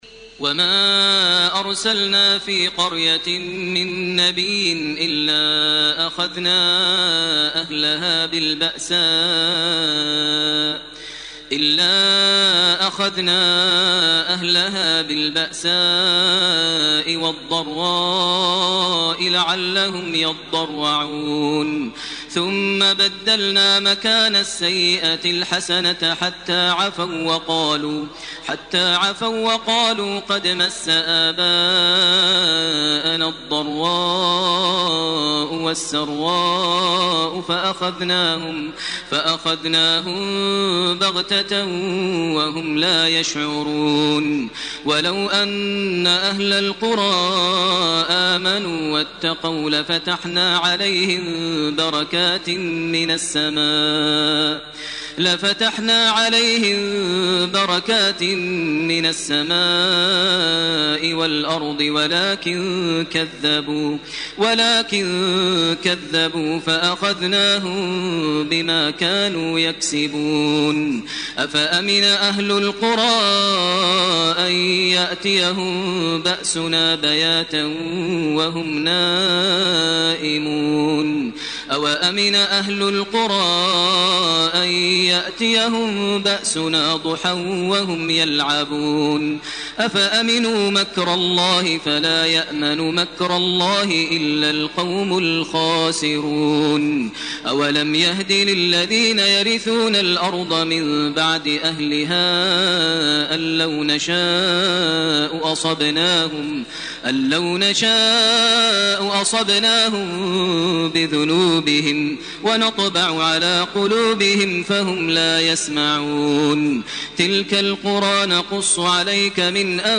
سورة الأعراف 94 - 171 > تراويح ١٤٢٨ > التراويح - تلاوات ماهر المعيقلي